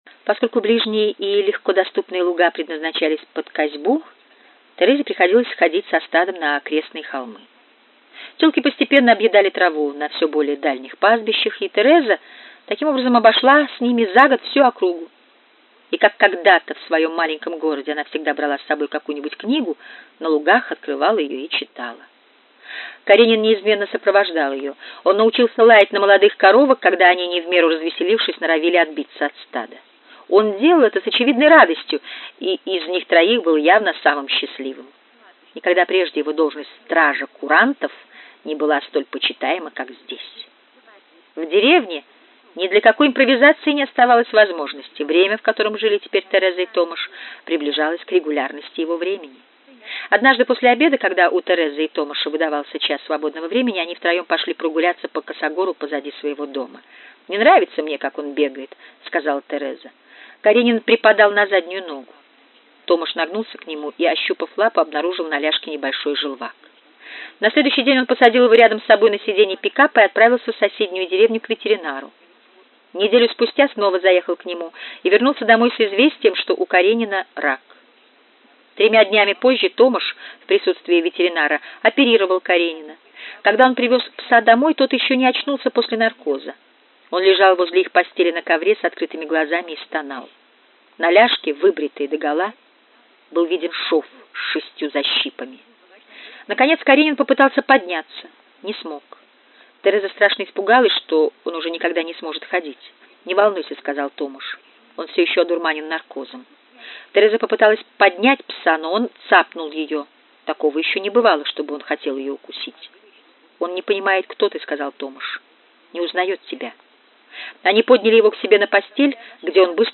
Аудиокнига Невыносимая легкость бытия
Качество озвучивания весьма высокое.